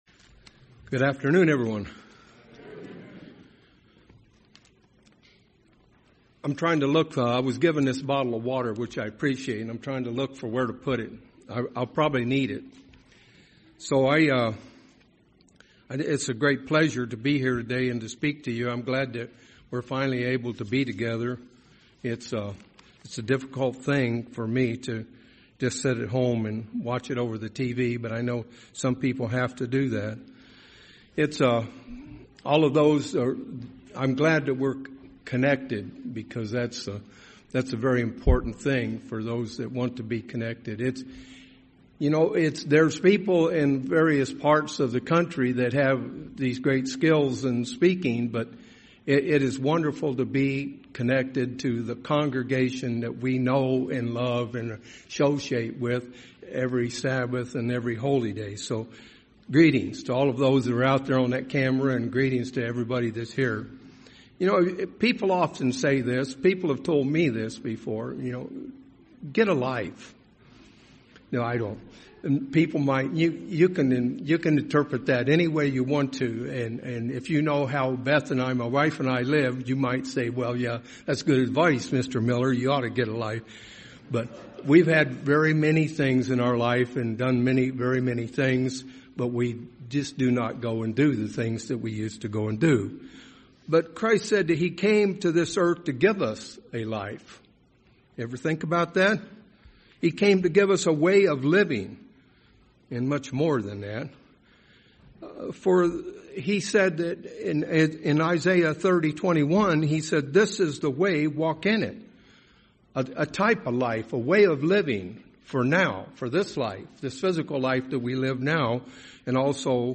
Sermon
Given in Phoenix Northwest, AZ